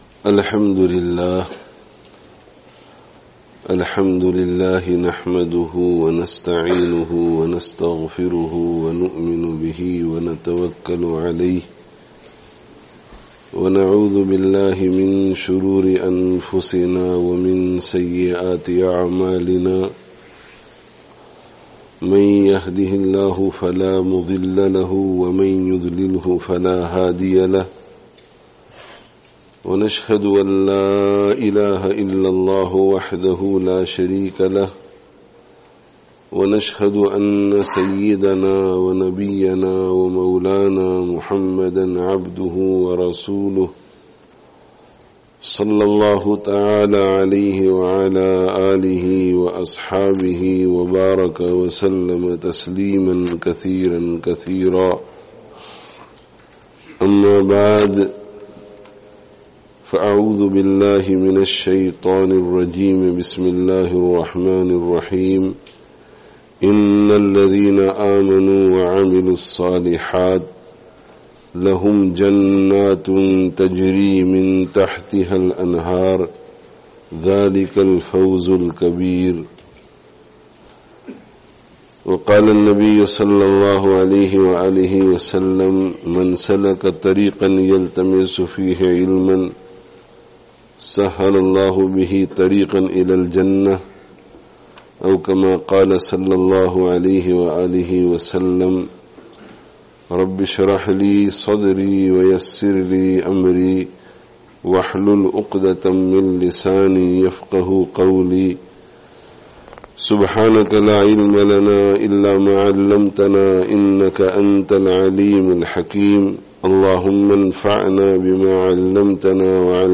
Haqīqī Kāmyābī Hāsil Karney kā Tarīqah [Rawdatul Ilm Academy - Annual Jalsah] (Masjid an Noor, Surrey, Canada 13/11/18)